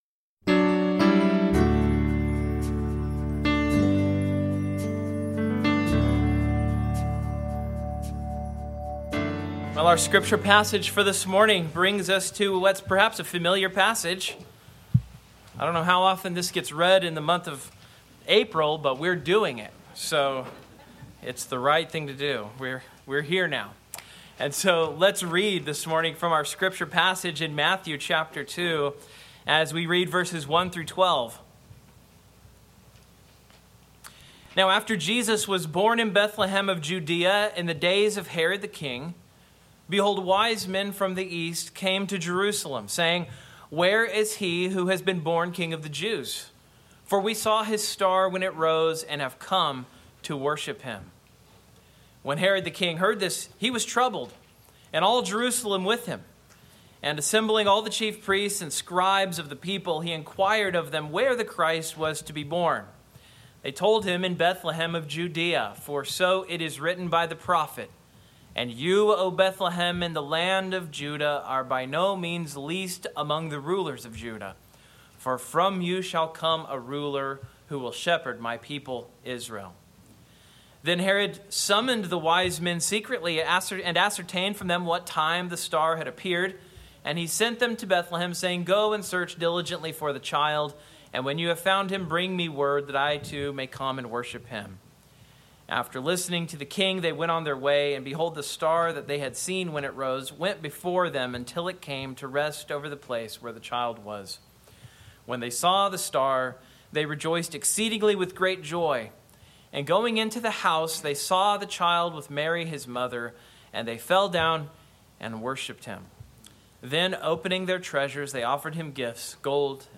Sermon Outline Main Point: Jesus Christ, as the fulfillment of Old Testament promises, is a source of divergent reactions, both from the Jews and Gentiles, even with his prophesied birth.